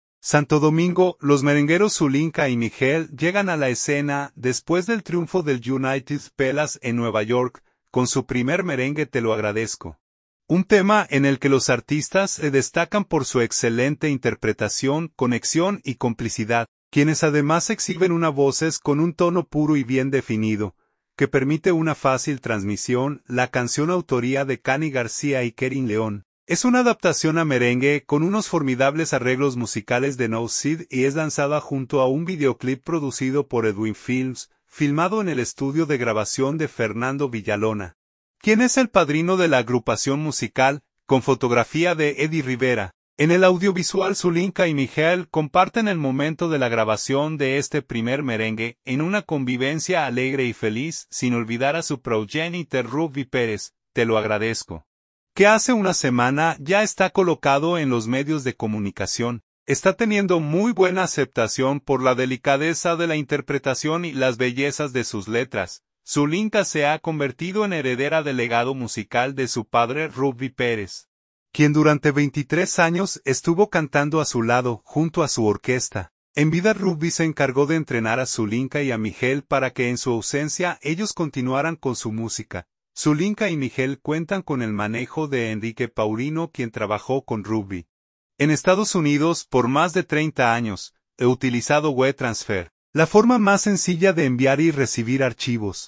es una adaptación a merengue